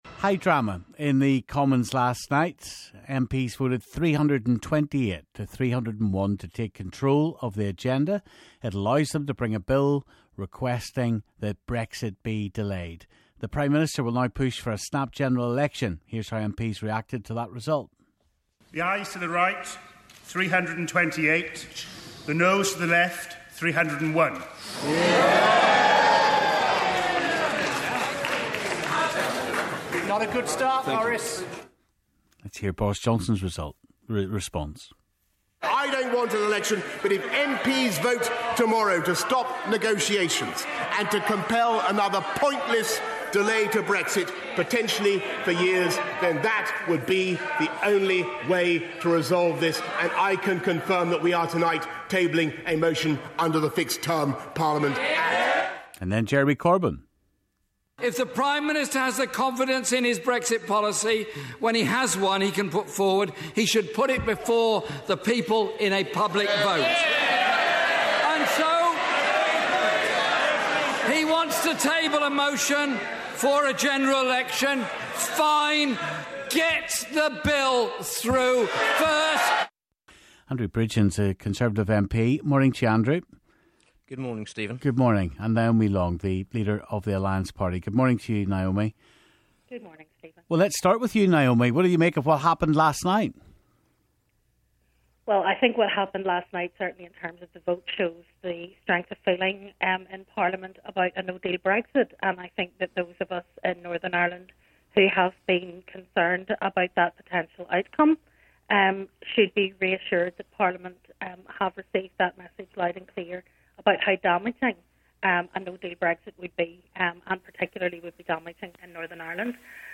Stephen spoke to Alliance party leader Naomi Long, Tory MP Andrew Bridgen and Sinn Féin's Chris Hazzard.